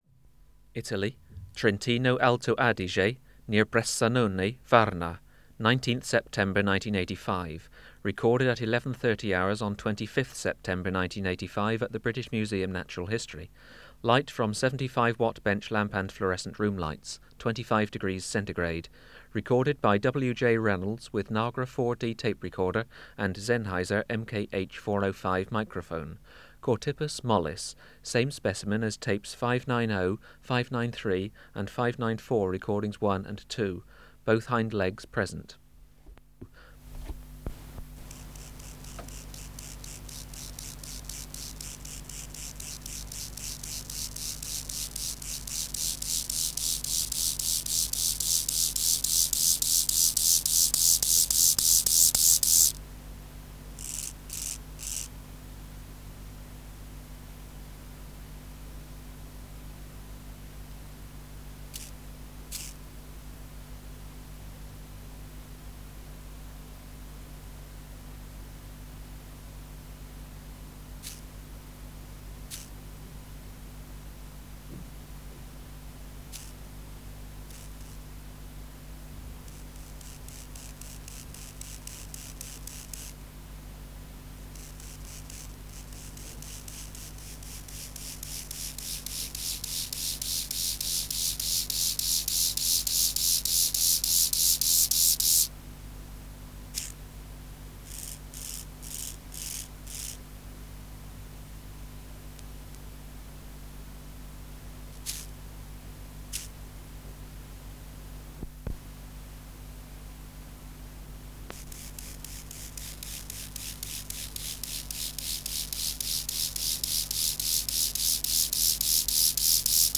Species: Chorthippus (Glyptobothrus) mollis ignifer
Recording Location: BMNH Acoustic Laboratory
Reference Signal: 1 kHz for 10 s
Substrate/Cage: Small recording cage
Female present. Courtship, 1 cm from female.
Microphone & Power Supply: Sennheiser MKH 405 Filter: Low Pass, 24 dB per octave, corner frequency 20 kHz